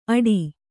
♪ aḍi